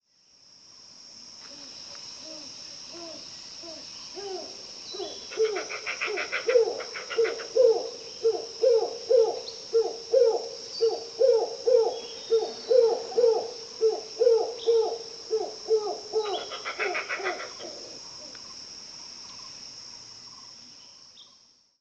Az üstökös szarvascsőrű (Berenicornis comatus) hangja
Az üstökös szarvascsőrű hangja leginkább egy mély, rekedt „kakukk”-hoz hasonlít. Gyakran hallatnak gyors, ismétlődő kiáltásokat, amelyek messzire elhallatszanak az erdőben.
• Mély hang: A hangjuk meglehetősen mély a többi szarvascsőrű fajhoz képest.
• Rezonancia: A nagy csőrük miatt a hangjuknak jellegzetes rezonanciája van.
Mély, rekedt „kakukk”
ustokos-szarvascsoru-hangja.mp3